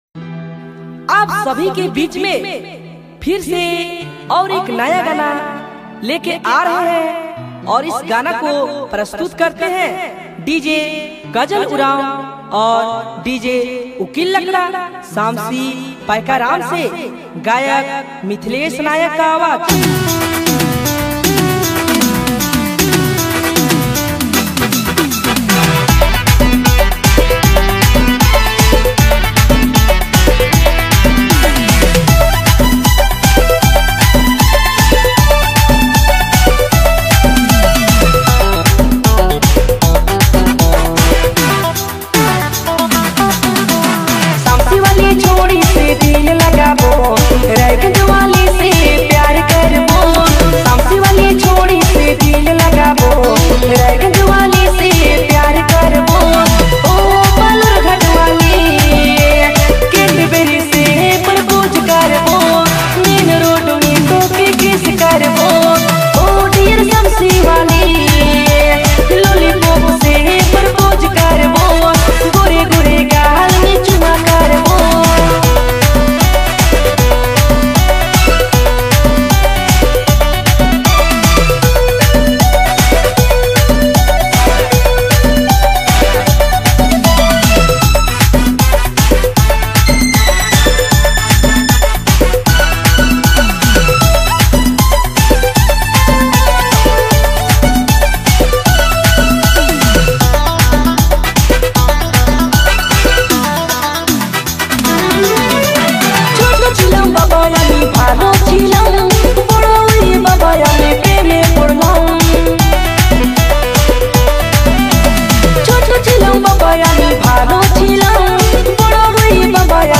All Dj Remix